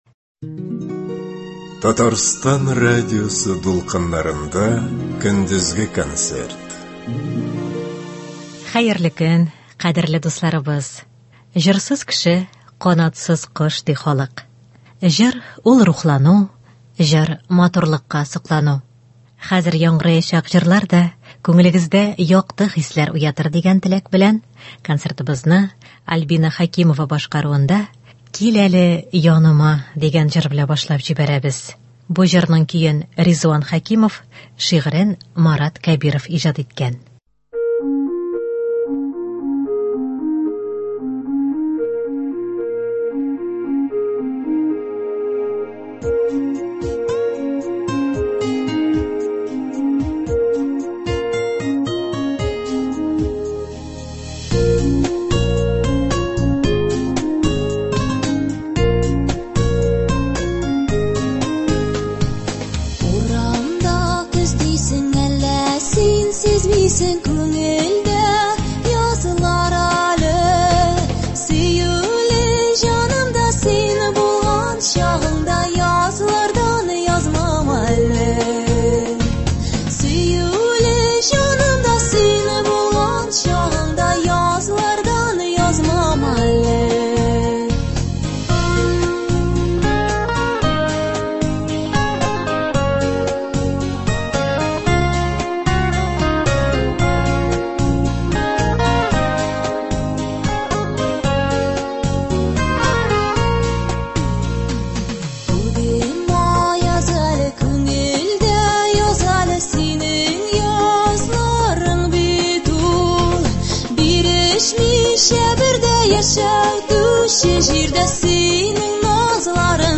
Көндезге концерт.
Лирик концерт .